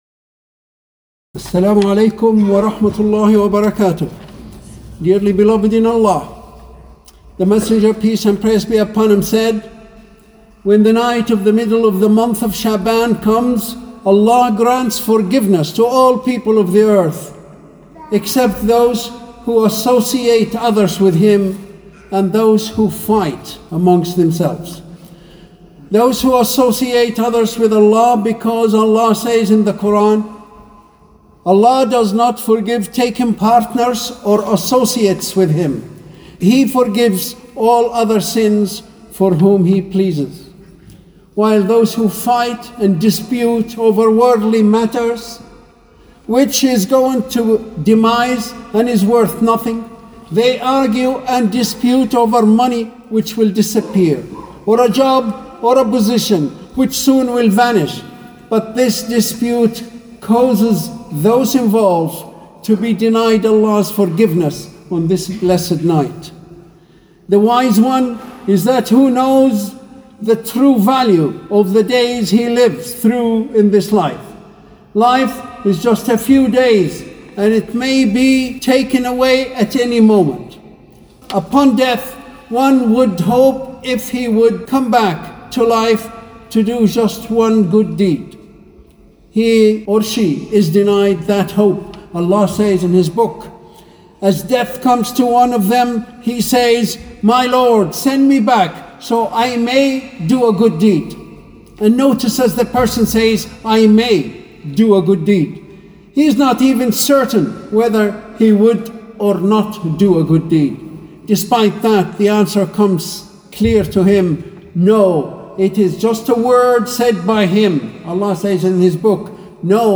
Friday Talks